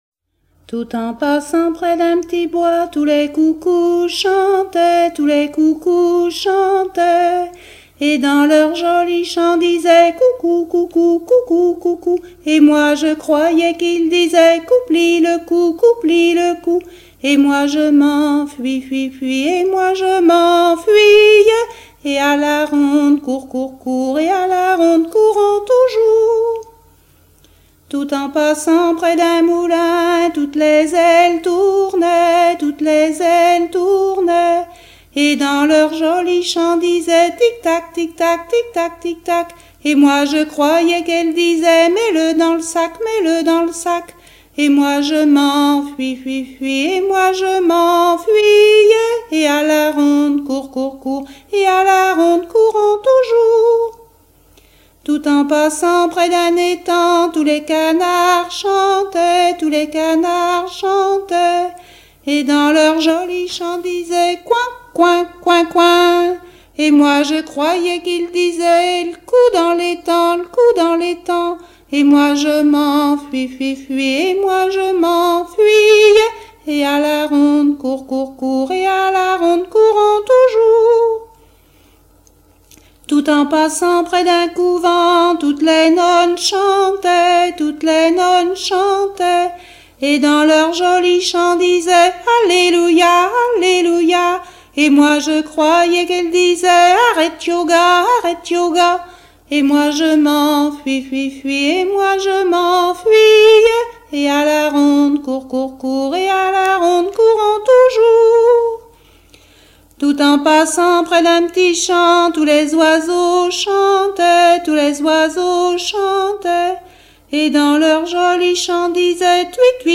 Tallud-Sainte-Gemme
Genre énumérative
Pièce musicale éditée